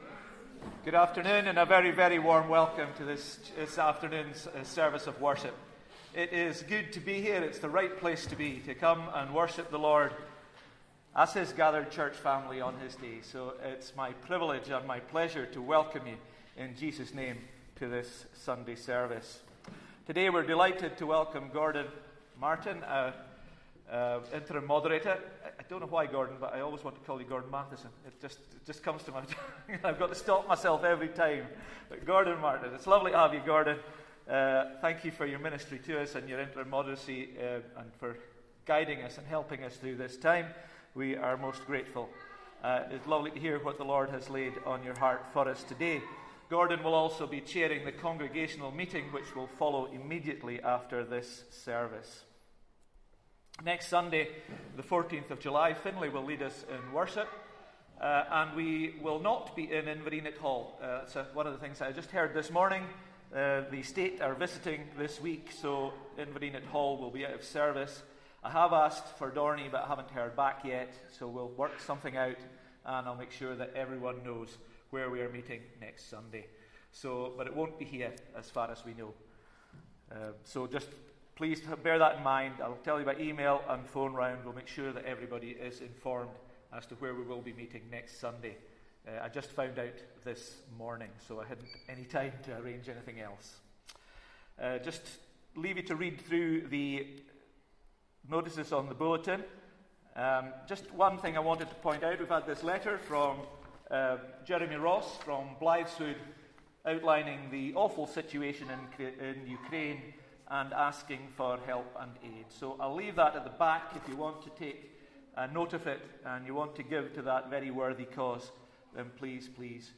Sunday-Service-7th-July-2024.mp3